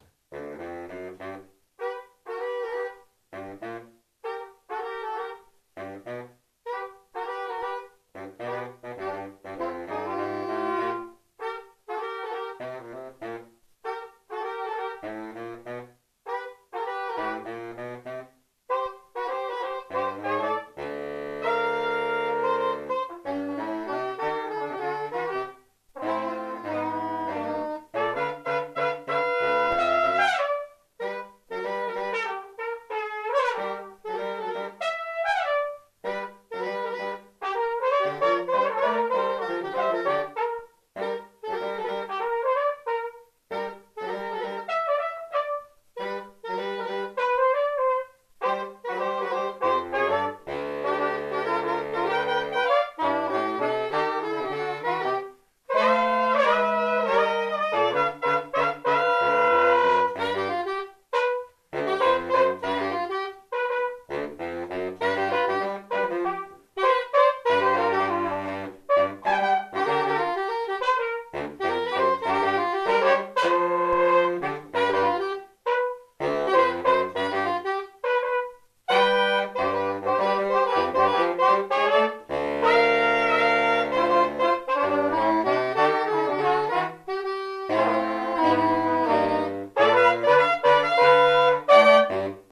· Genre (Stil): Jazz